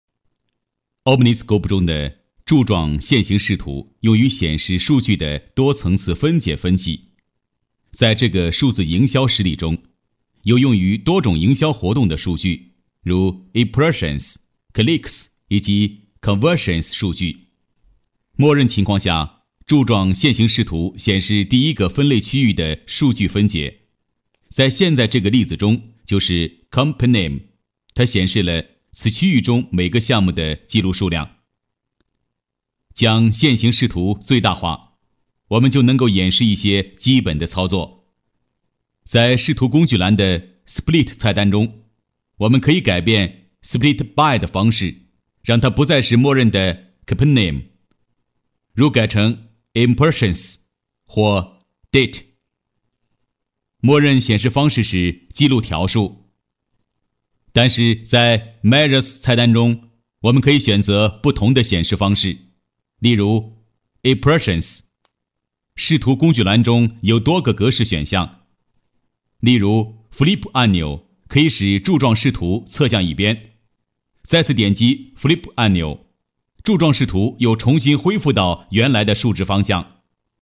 男国162_多媒体_培训课件_营销教学课件.mp3